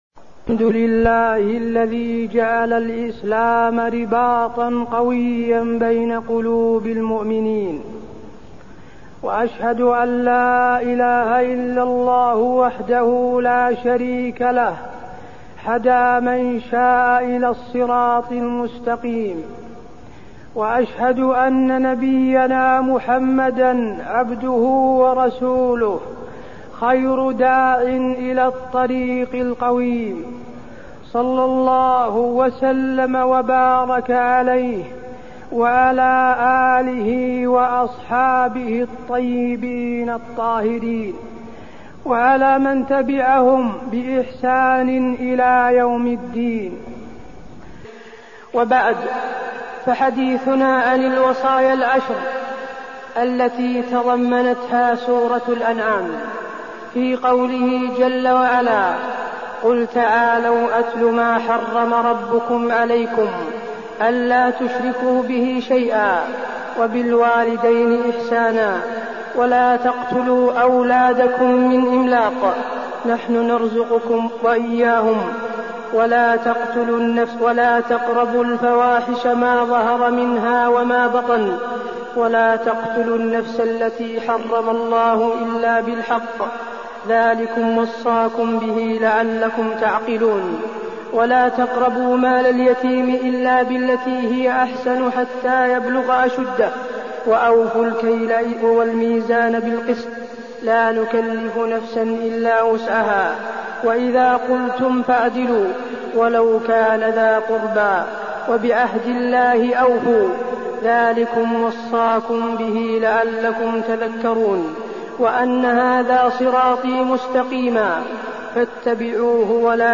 تاريخ النشر ٢٣ ذو الحجة ١٤١٩ هـ المكان: المسجد النبوي الشيخ: فضيلة الشيخ د. حسين بن عبدالعزيز آل الشيخ فضيلة الشيخ د. حسين بن عبدالعزيز آل الشيخ الوصايا التي في سورة الأنعام